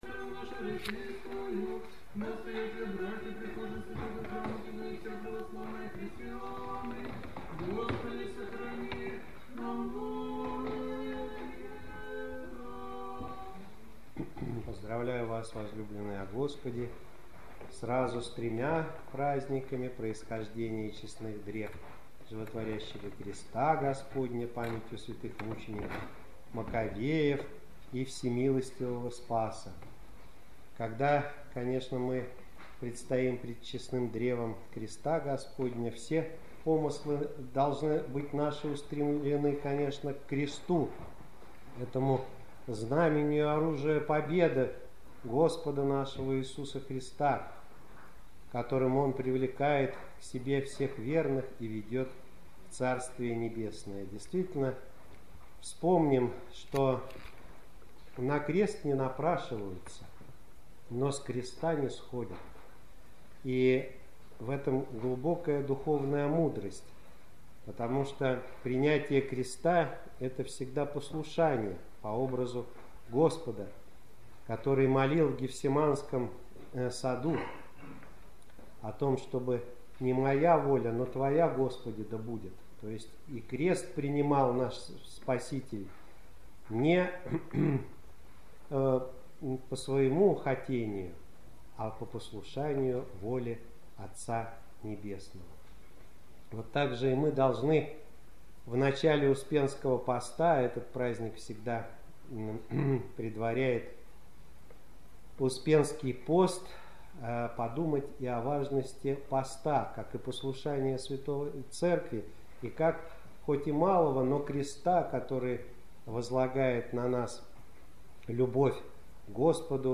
14.08.2014 Седмица 10-я по Пятидесятнице - проповедь на Литургию память Происхождения (изнесения) древ Животворящего Креста Господня